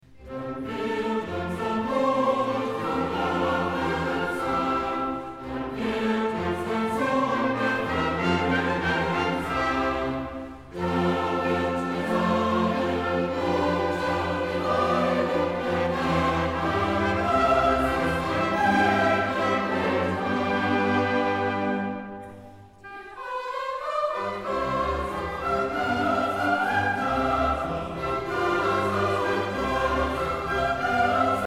Erbaulich, feierlich und ehrwürdig